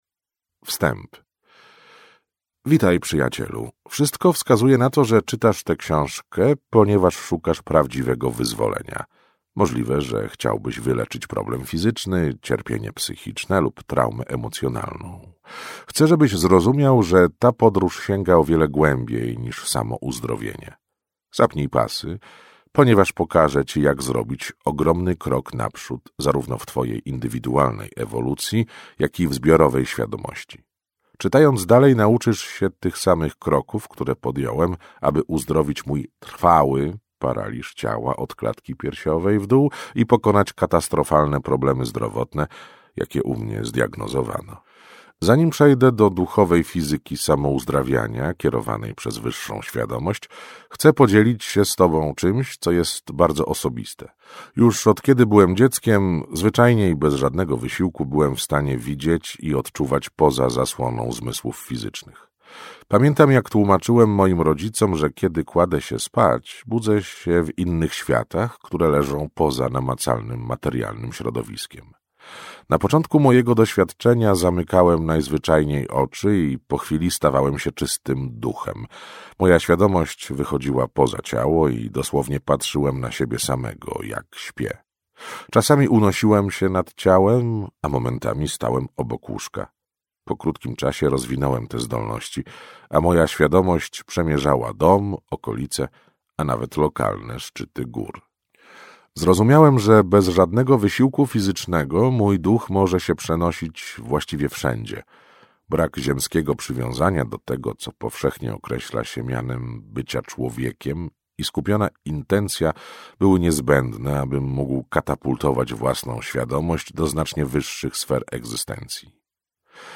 Audiobook MP3